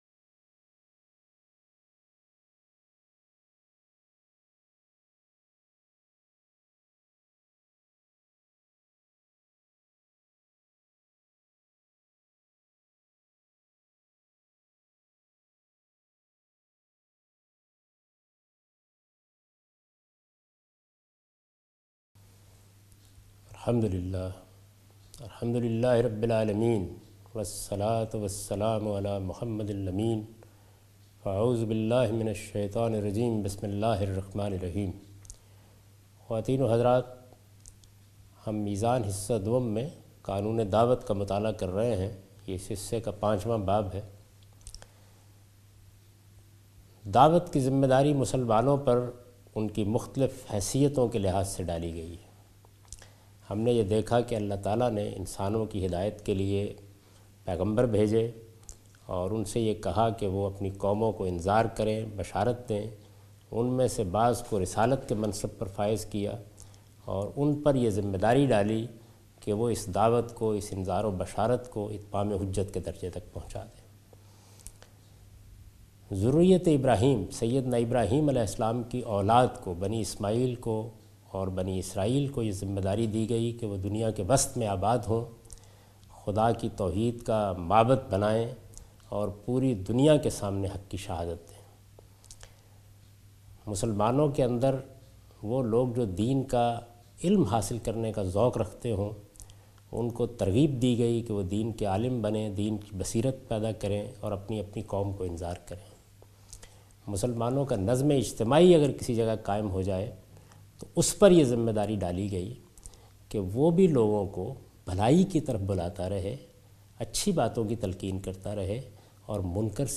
A comprehensive course taught by Javed Ahmed Ghamidi on his book Meezan.